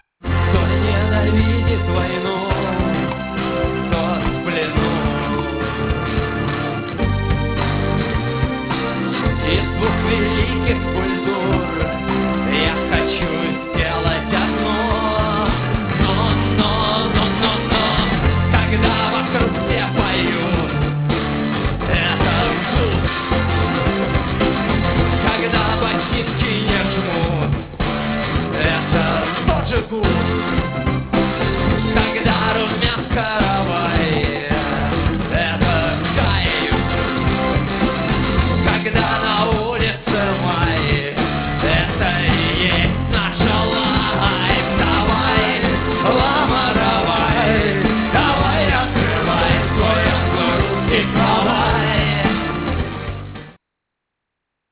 ДК им. Горбунова (1996)
фрагмент песни ( 55 сек.)
AUDIO, stereo